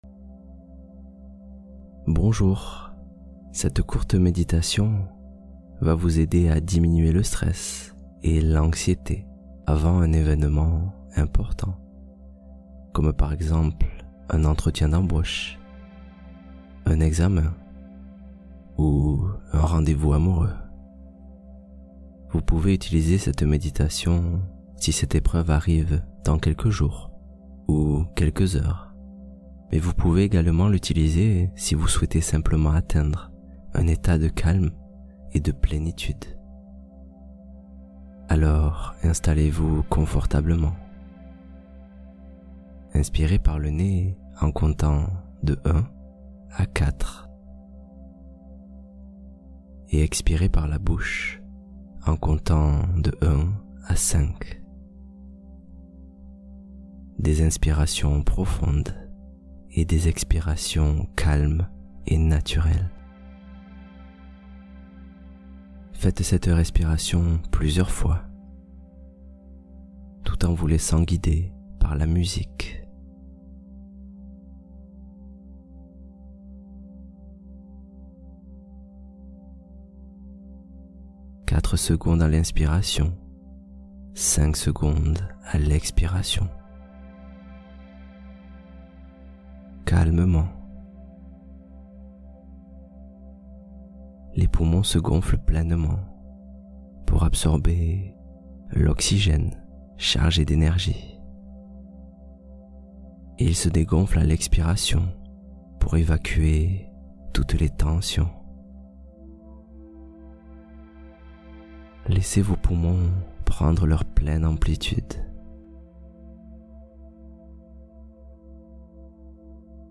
Vaincre le stress avant un événement important : méditation guidée